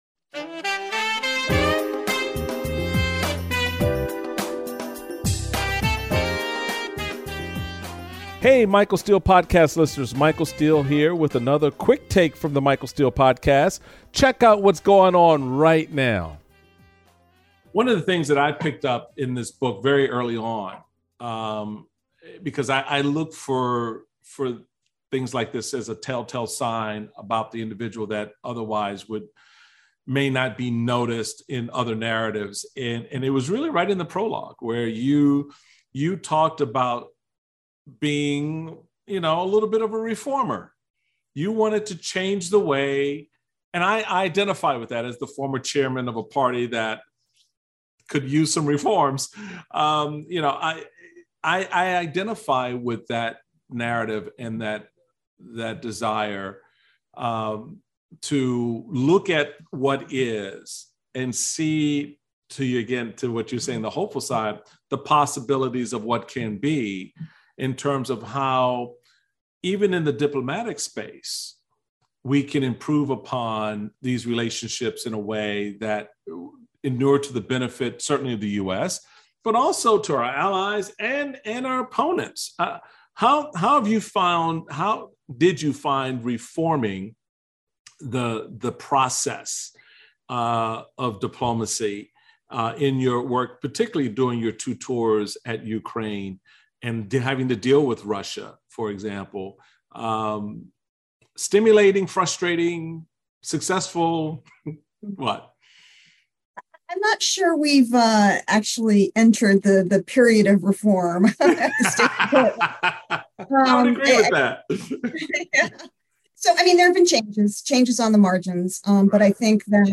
Former U.S Ambassador to Ukraine Marie Yovanovitch joins The Michael Steele Podcast to discuss her new memoir, "Lessons From the Edge."